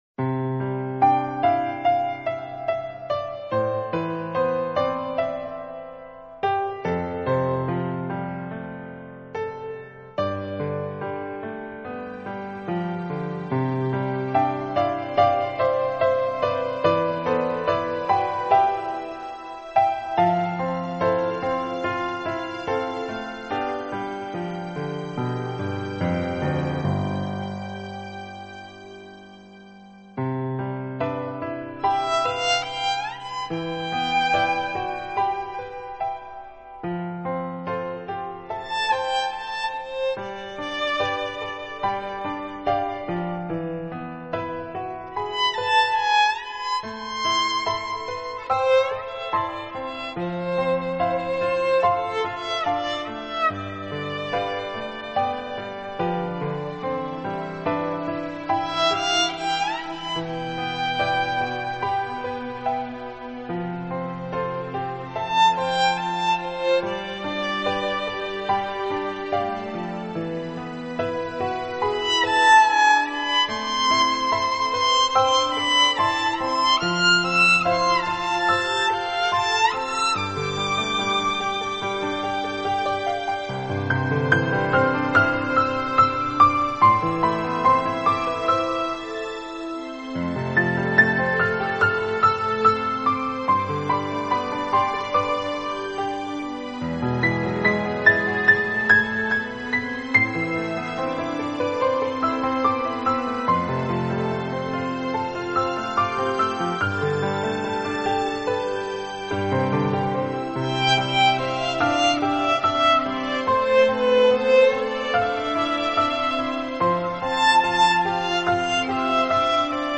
小提琴--《悄悄地告诉你》
听，黑管与长笛缠绕缱绻如丝的爱意, 听，大提琴与小提琴合鸣凝神的倾听, 听，键盘上黑色精灵与白色天使， 悠然舞动藏在夜风里的秘密心曲。